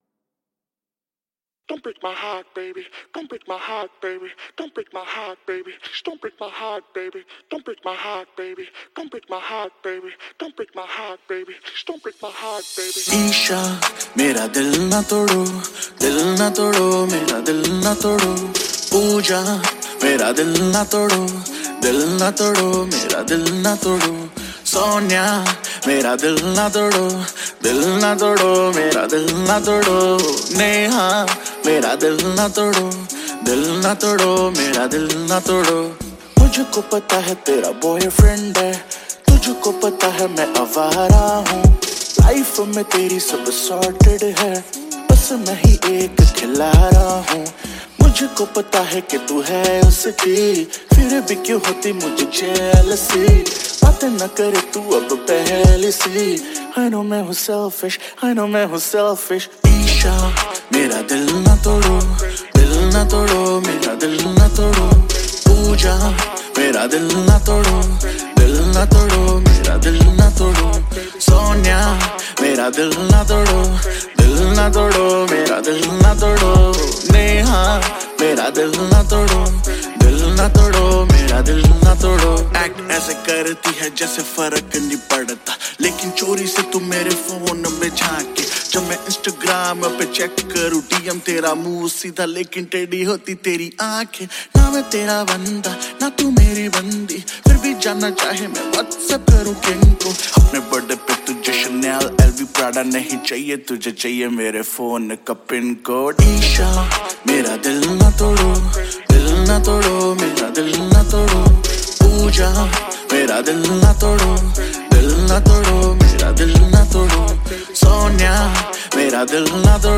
Hindi Pop Album Songs 2023